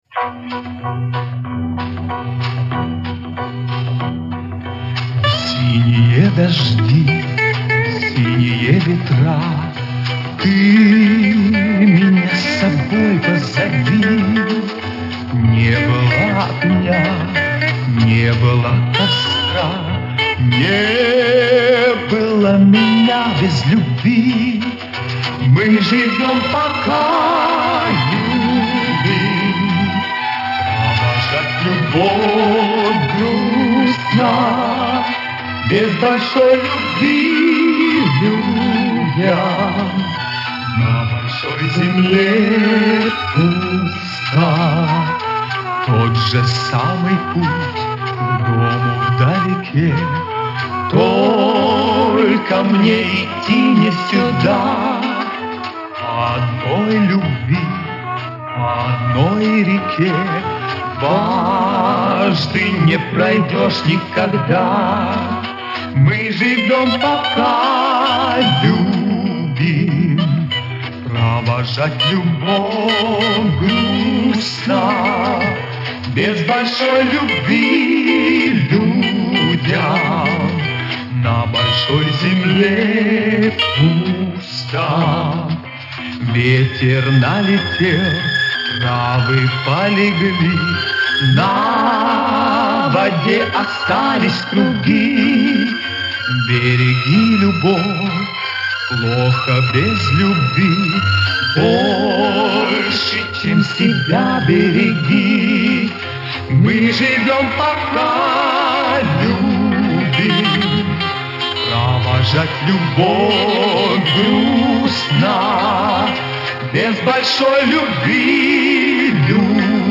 Студийная запись.
Тут скрипки звучат.
Это, похоже, тоже студийный вариант.